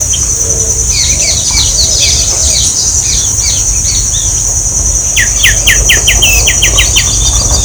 Anambé Común (Pachyramphus polychopterus)
Nombre en inglés: White-winged Becard
Provincia / Departamento: Entre Ríos
Localización detallada: Colonia Ayuí, Punta Norte
Condición: Silvestre
Certeza: Fotografiada, Vocalización Grabada